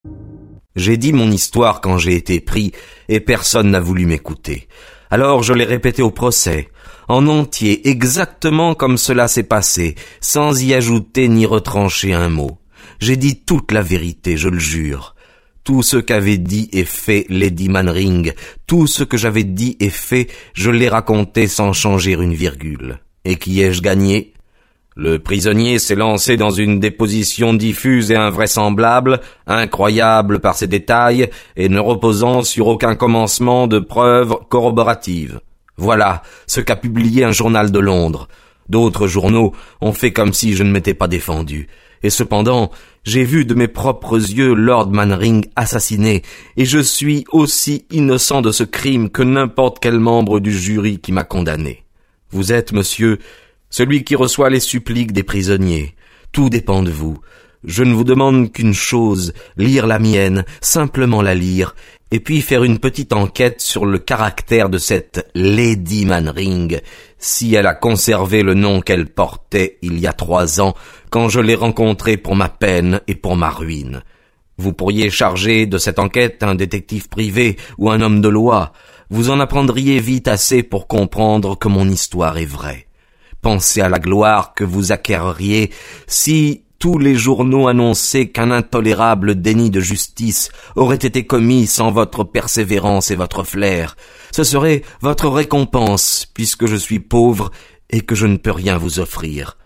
Click for an excerpt - B24 de Arthur Conan Doyle